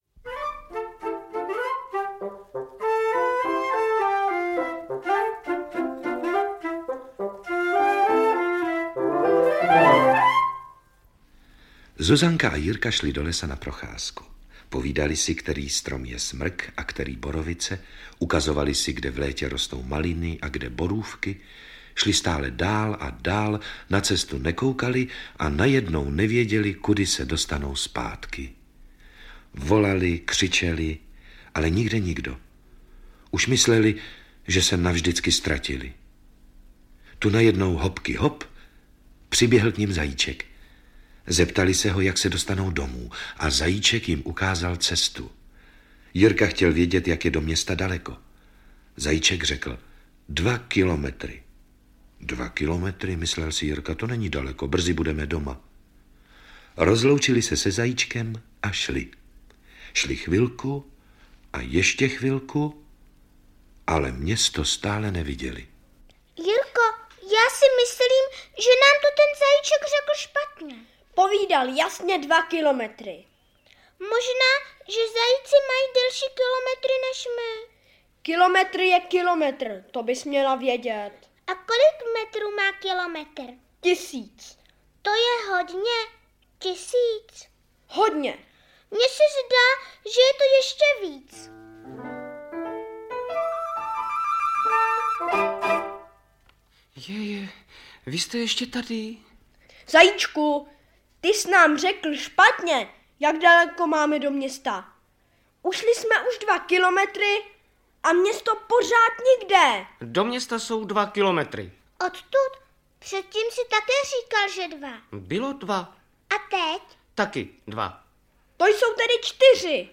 Výběr pořadů školského rozhlasu pro 1.-5. ročník ZDŠ I. - Ludwig Górski - Audiokniha